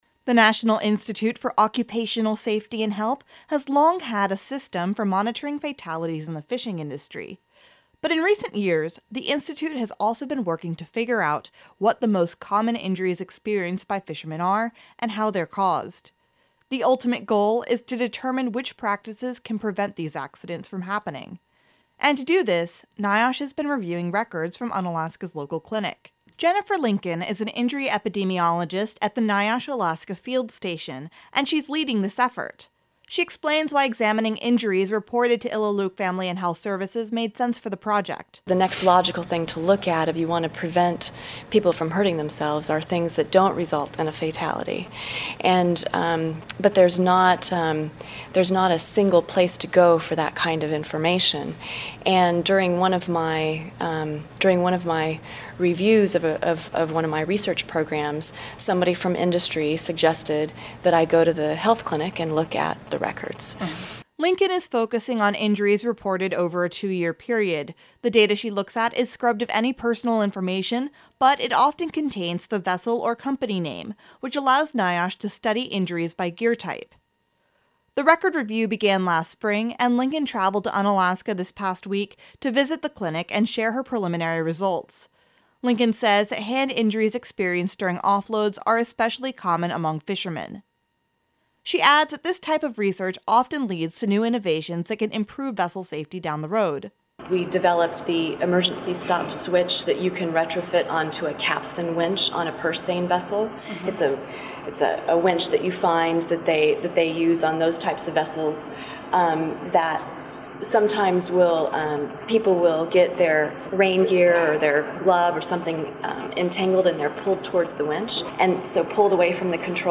By KUCB News